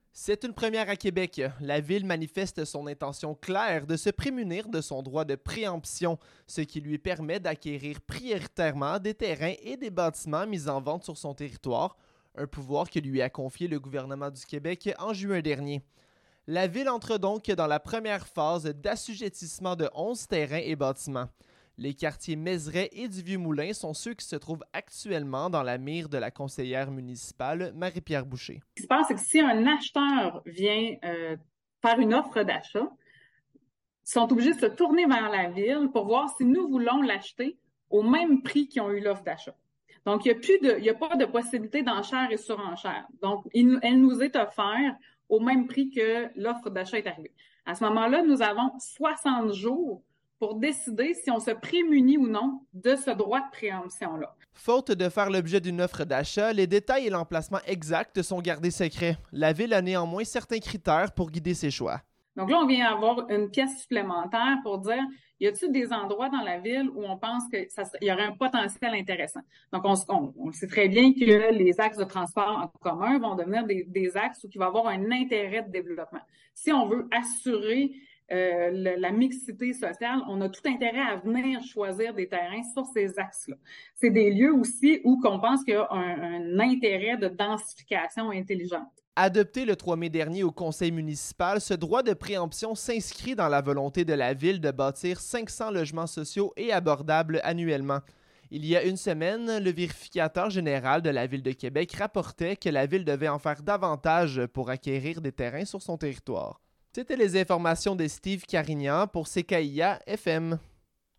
Reportage-preemption.mp3